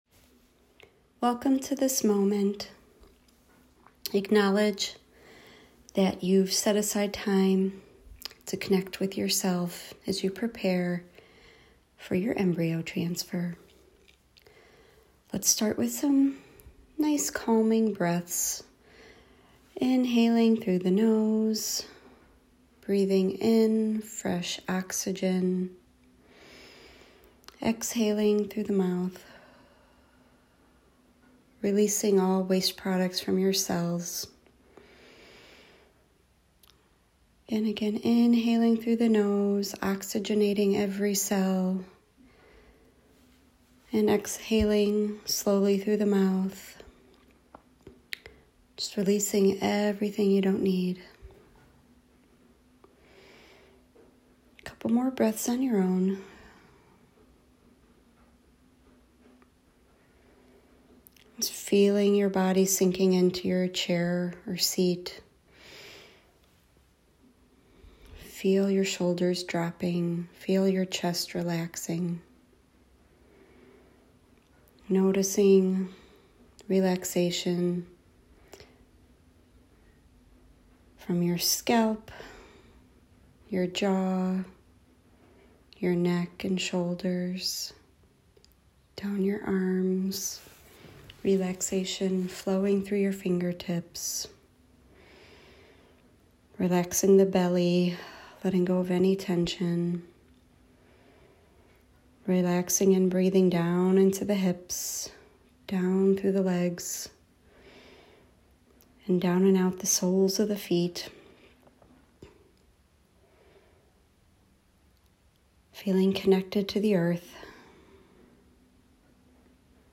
Embryo Transfer Guided Meditation
Pre-Embryo-Transfer-Guided-Meditation.m4a